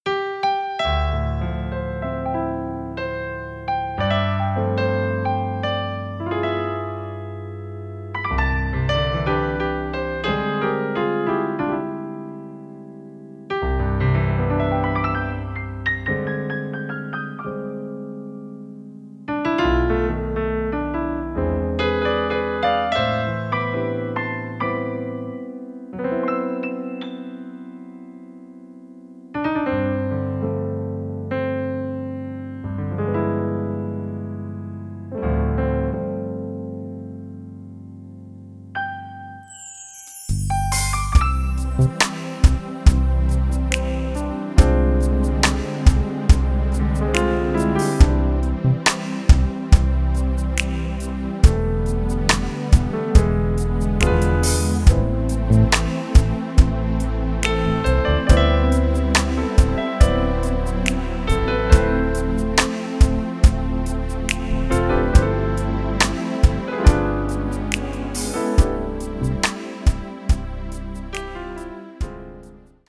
No lead guitar solo.
Key of C
Backing track only.